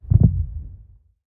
heartbeat_3.ogg